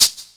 007_Lo-Fi Shaker Hi-Hat_1.L.wav